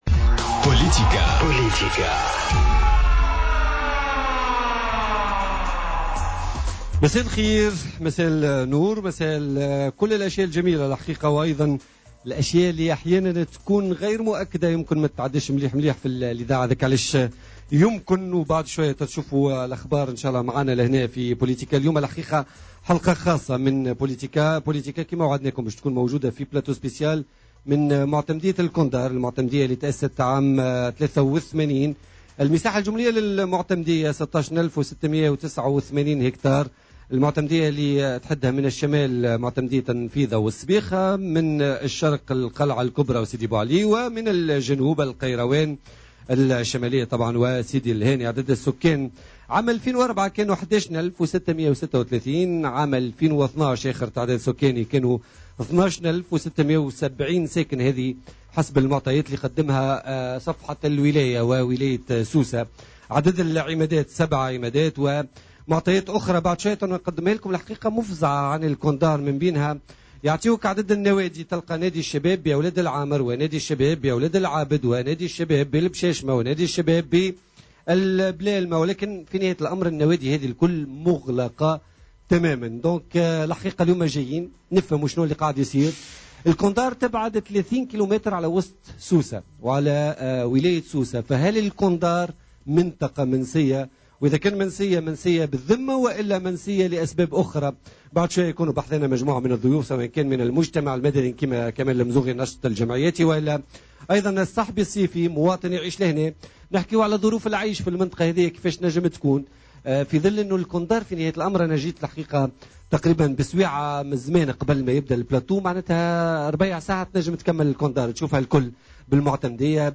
حصة خاصة مباشرة من معتمدية كندار